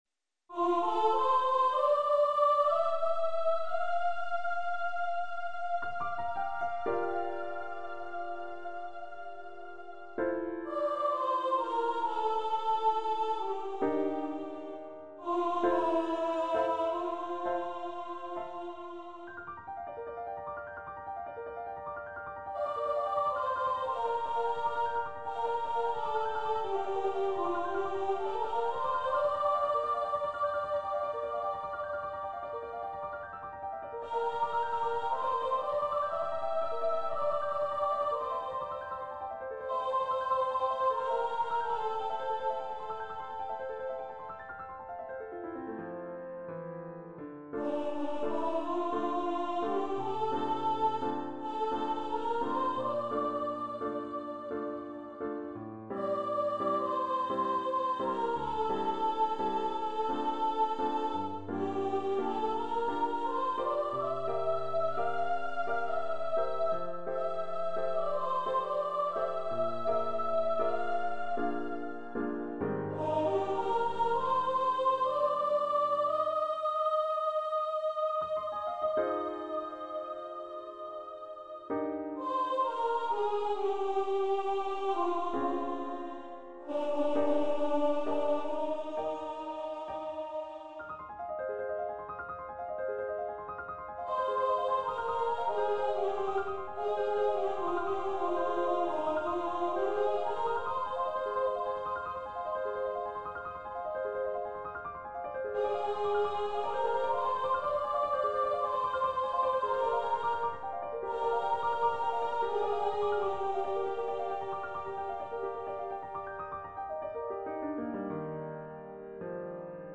Composer's Demo